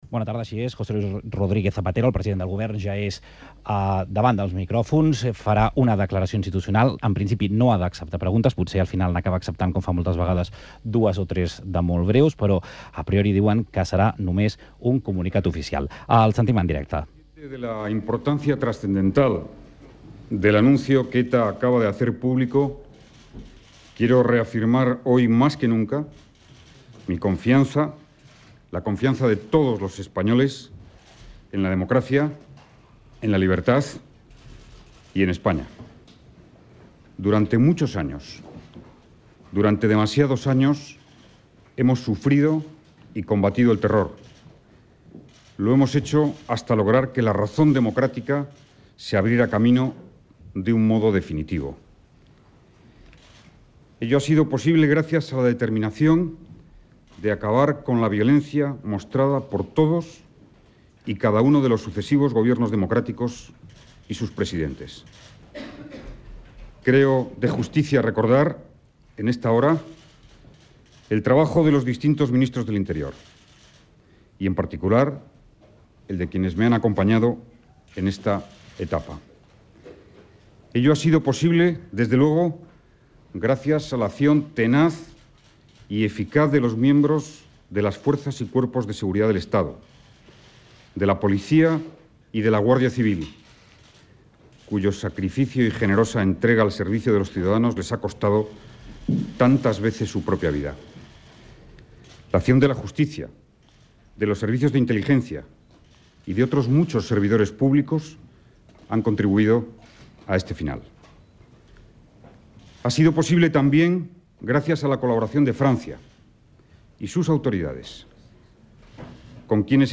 Presentació i declaració institucional del president del Govern Espanyol José Luis Rodríguez Zapatero arran del comunicat de cessament definitiu de l'activitat armada de la organització terrorista ETA.
Informatiu